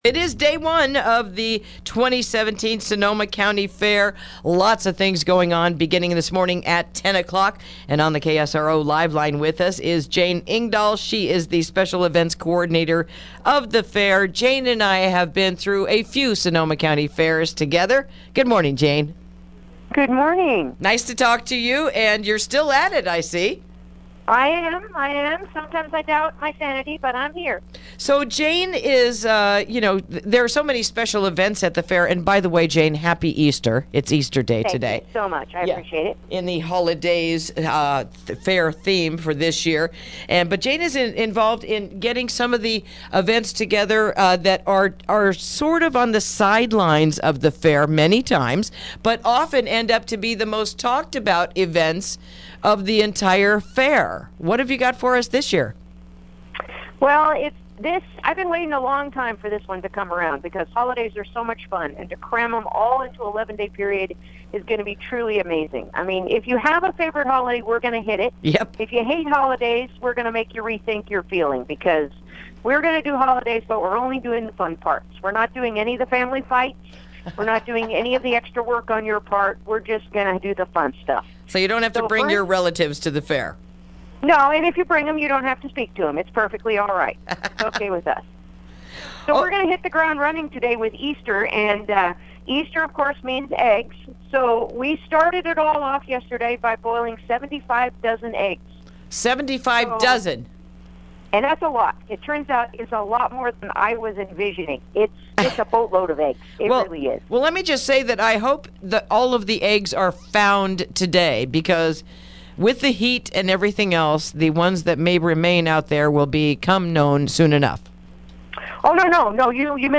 Interview: Sonoma County Fair Kicks Off Today